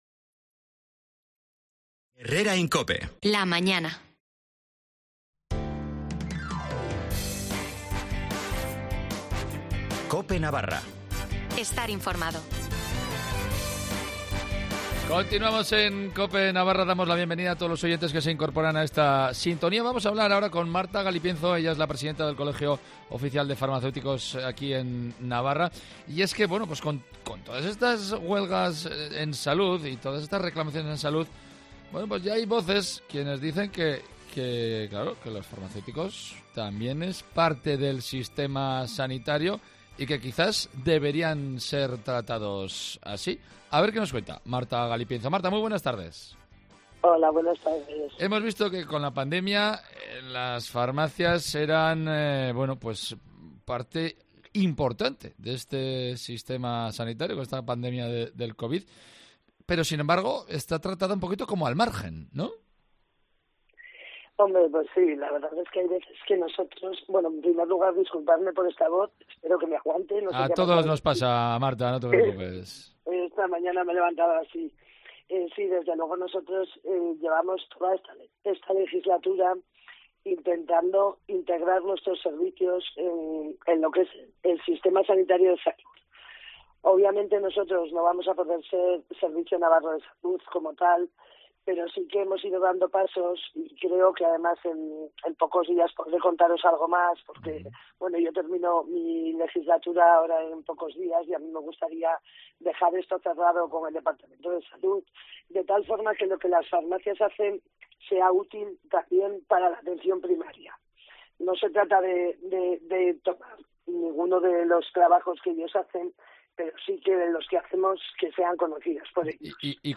ha anunciado en los micrófonos de Cope Navarra que pronto habrá novedades con respecto a las farmacias en Navarra. Se trabaja con el Gobierno de Navarra para que las farmacias puedan entrar de alguna manera en el Sistema Sanitario. Además, también ha hablado sobre las guardias de las farmacias.